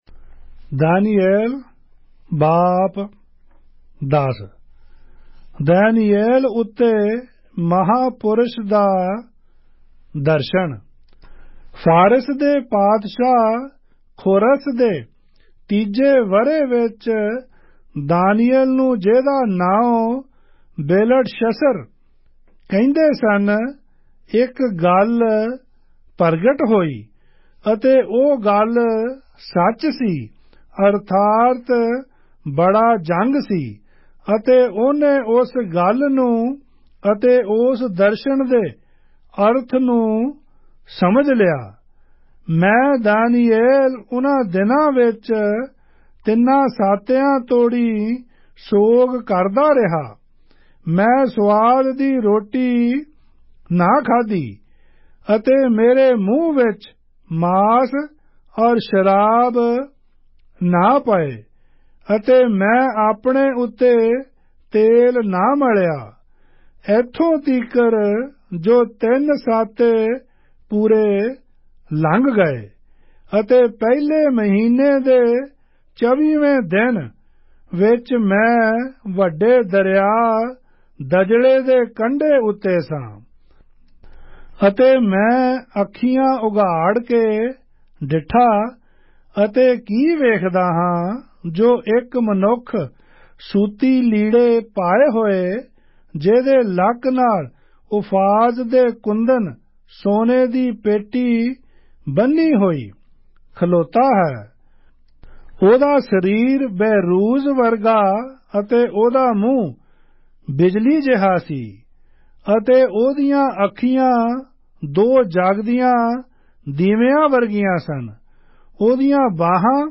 Punjabi Audio Bible - Daniel 1 in Mhb bible version